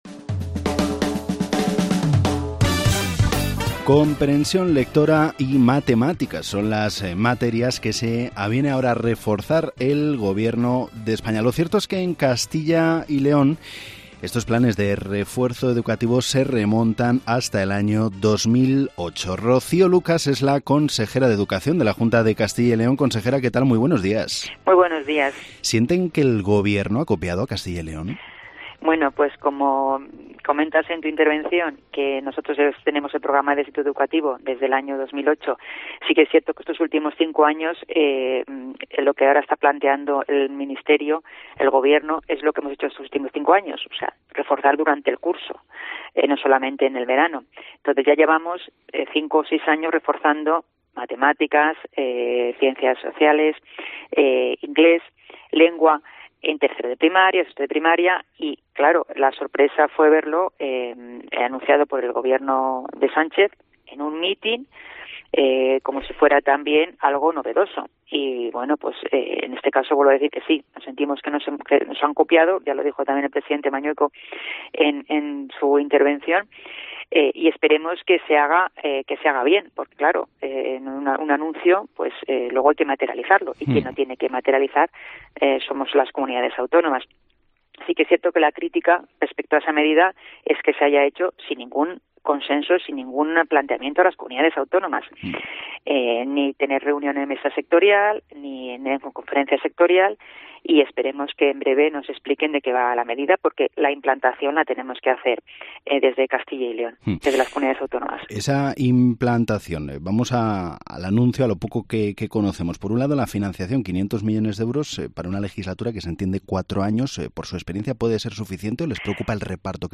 Entrevista a la consejera de Educación de Castilla y León, Rocío Lucas, en Herrera en COPE